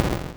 hard-hit.wav